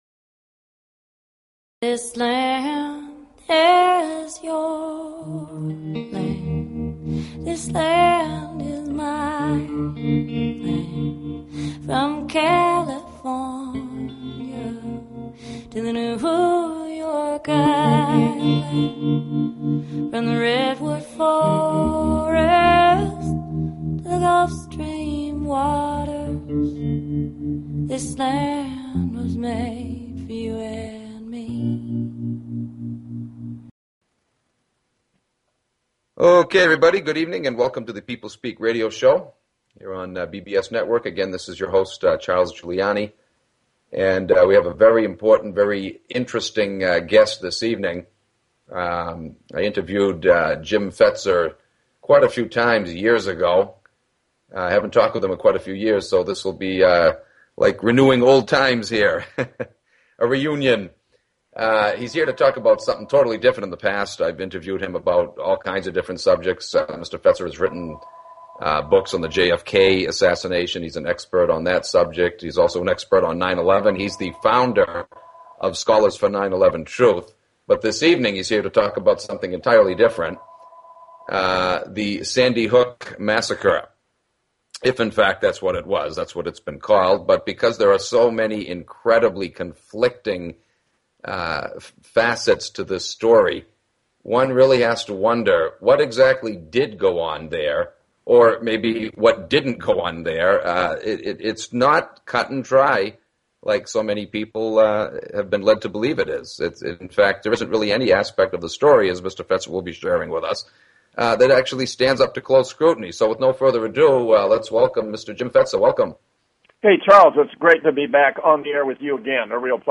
The People Speak has evolved over the years with many great guests who have been interviewed by some very fine hosts.
The show features a guest interview from any number of realms of interest (entertainment, science, philosophy, healing, spirituality, activism, politics, literature, etc.).